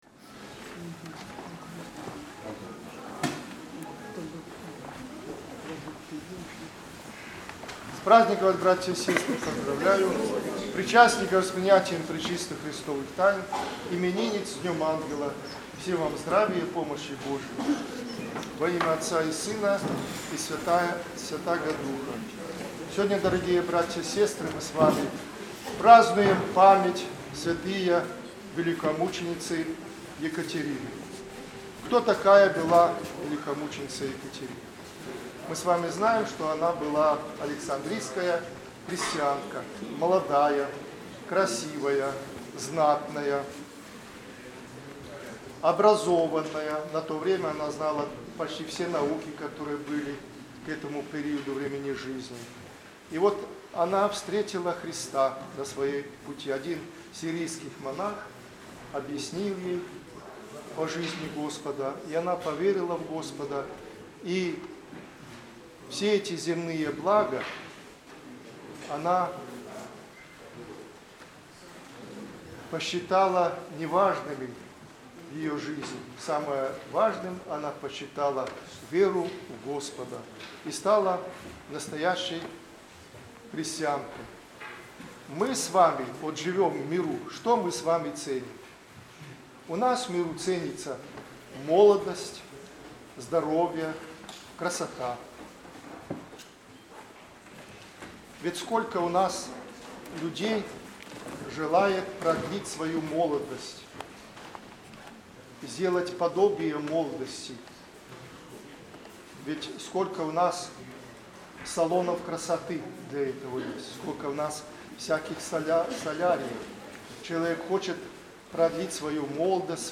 Проповедь прот.
в праздник св. вмц. Екатерины